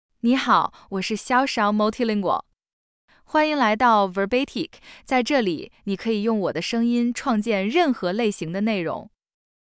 Xiaoxiao MultilingualFemale Chinese AI voice
Xiaoxiao Multilingual is a female AI voice for Chinese (Mandarin, Simplified).
Voice sample
Listen to Xiaoxiao Multilingual's female Chinese voice.
Female